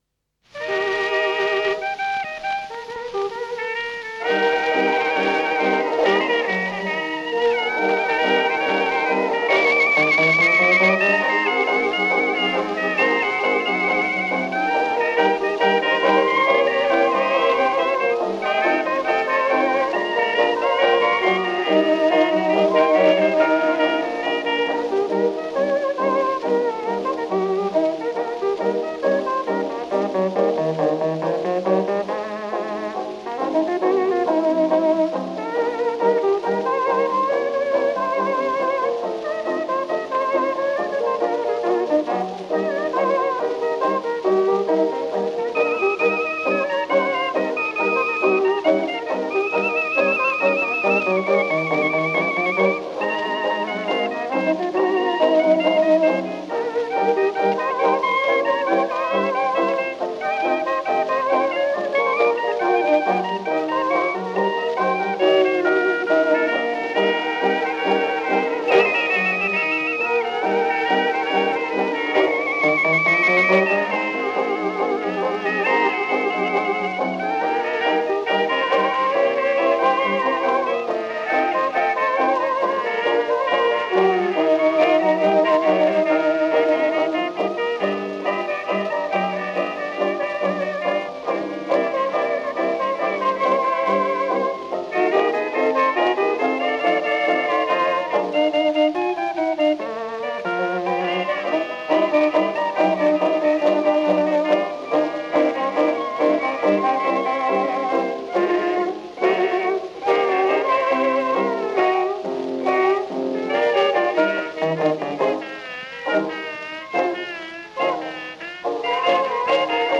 an Atlanta-based dance band
piano
trumpet
drums and xylophone
banjo
violin
clarinet and saxophone